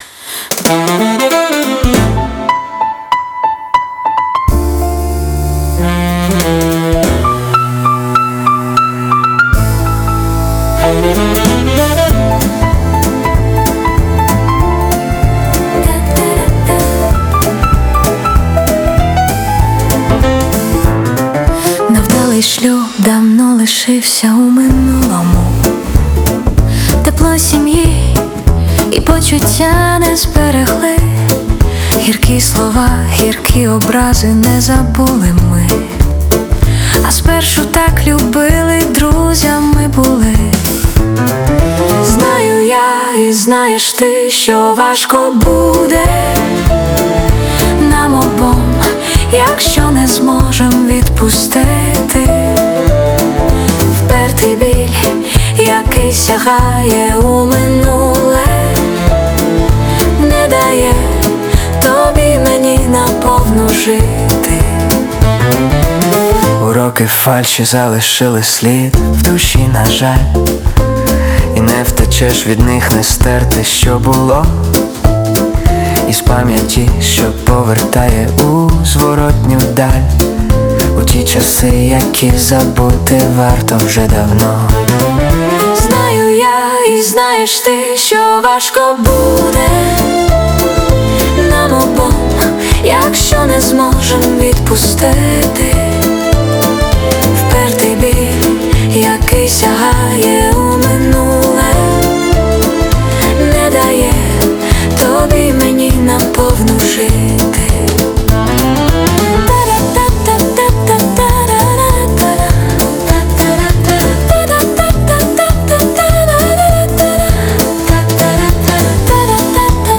Стиль: Фолк-поп